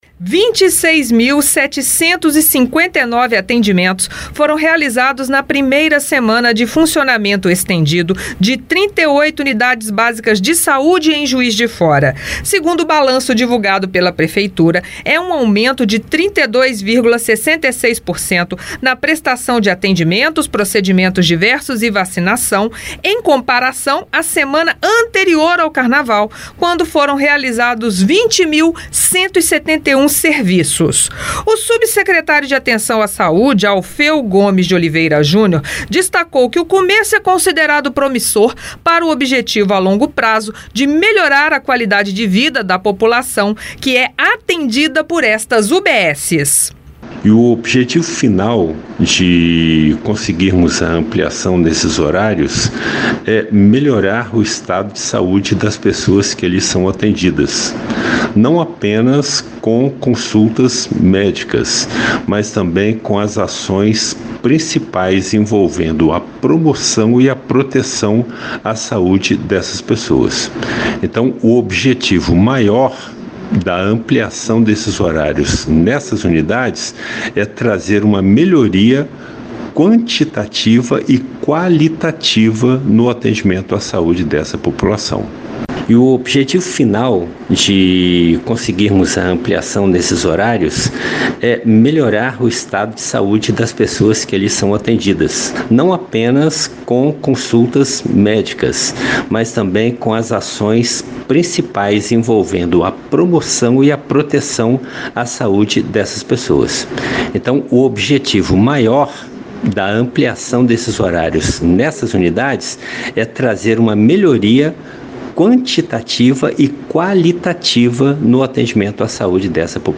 O subsecretário de Atenção à Saúde, Alfeu Gomes de Oliveira Júnior analisou o resultado. Ouça na reportagem.